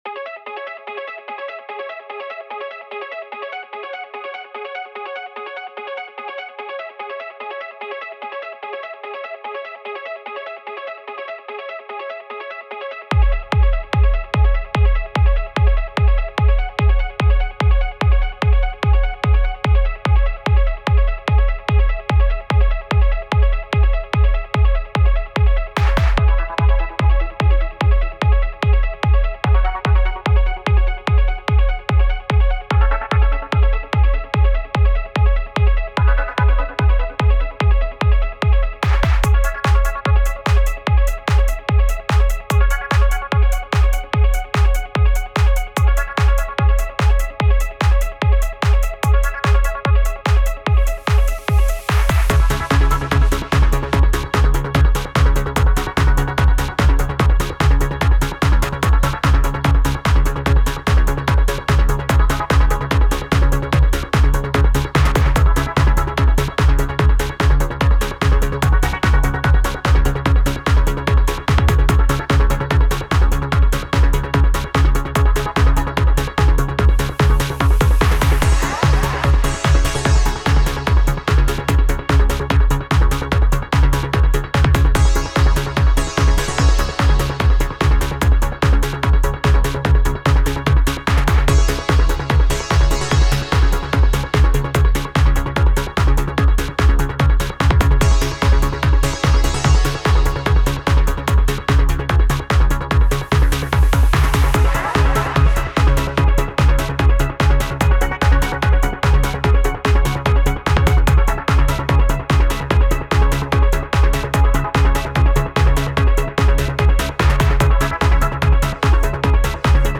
Past is present - Trance (oldschool style, unmastered)
Also keine vorgebauten Loops usw, alles Handarbeit (was bei so einem einfachen Song ja auch kein Hexenwerk ist). Man hört hier einen KORG Prophecy und ein paar selbergemachte Sounds. Und, der Song ist NICHT gemastert , weil ich noch gar keine Ahnung habe, wie ich da vorgehen muss hehe.
Eigentlich gibts es nur handgemachtes MIDI und Prophecy Sound und ein paar Ableton Standard sounds.